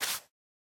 brushing_generic1.ogg